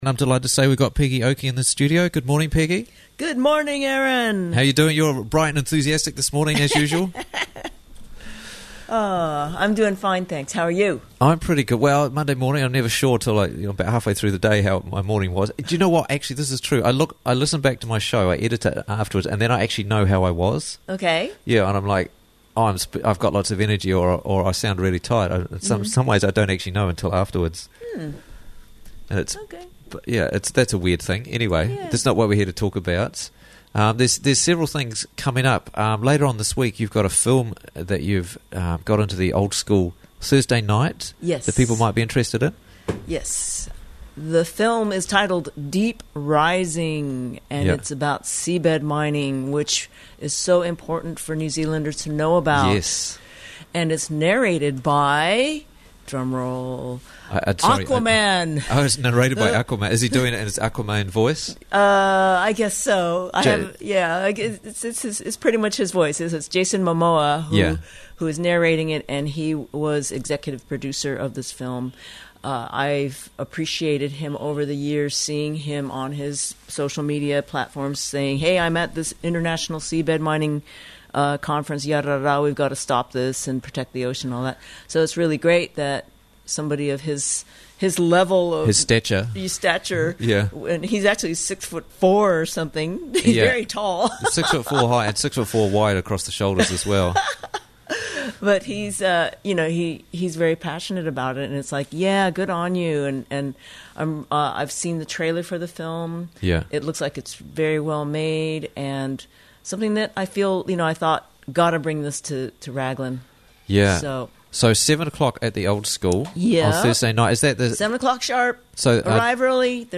Deep Rising - Free Movie Thursday - Interviews from the Raglan Morning Show